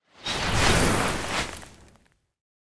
Free Frost Mage - SFX
freezing_gush_16.wav